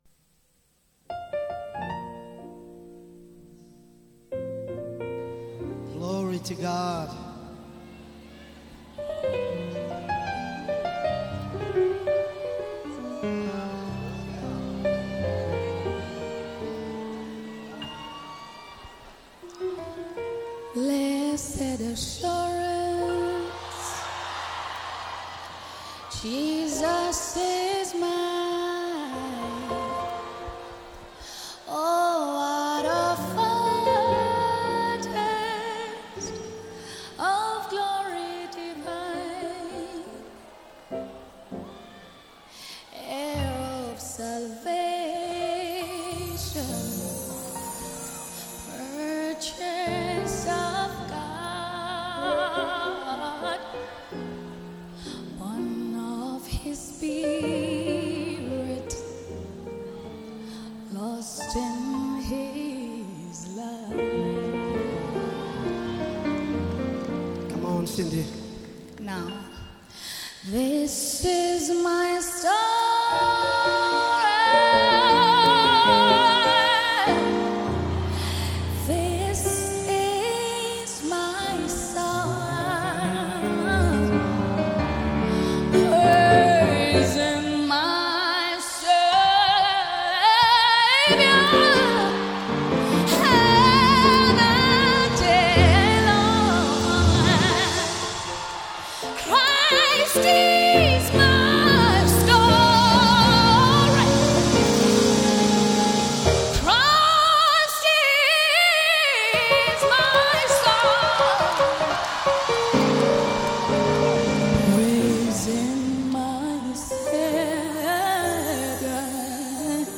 Genre: Gospel/Christian.
Live performance.